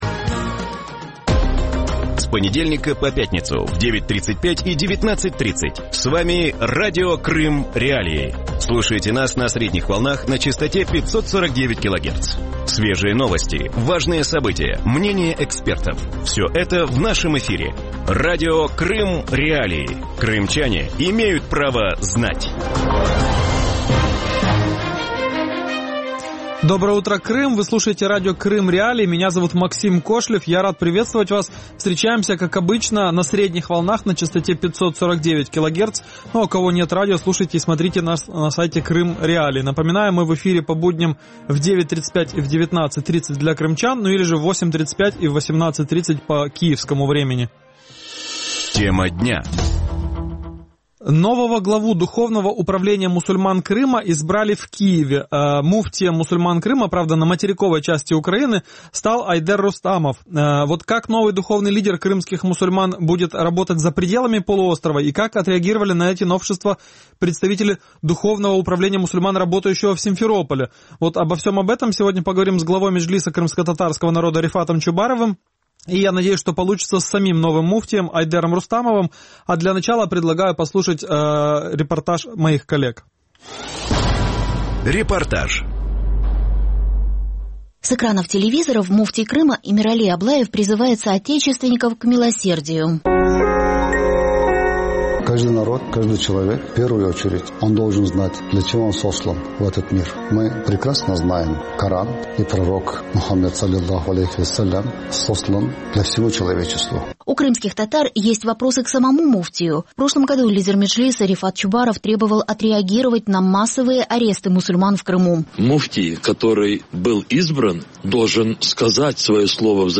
Утром в эфире Радио Крым.Реалии говорят о избрании нового главы Духовного управления мусульман Крыма в Киеве. Айдер Рустамов стал муфтием полуострова. Как новый муфтий планирует работать за пределами полуострова и что думают о создании ДУМКа в Крыму?